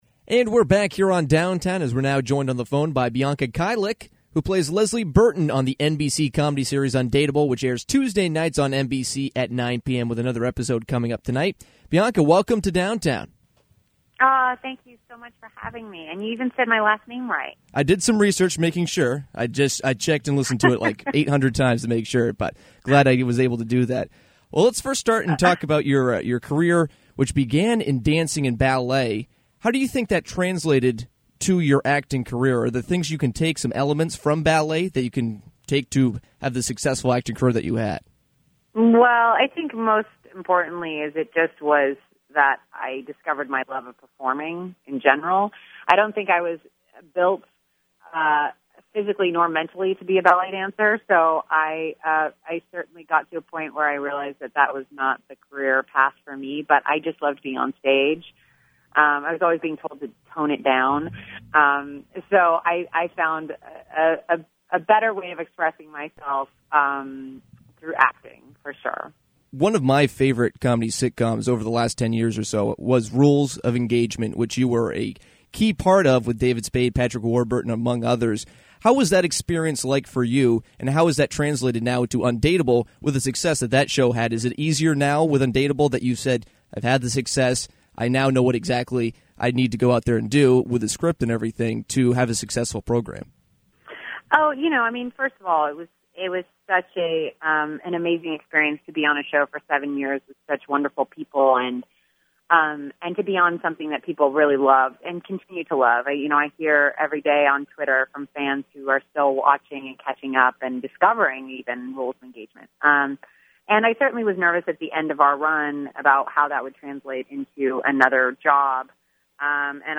Bianca Kajlich joined Downtown to talk about her career and her comedy sitcom “Undateable”. Kajlich was in “Rules of Engagement” and was also featured in movies like “Bring It On” and “30 Minutes Or Less”.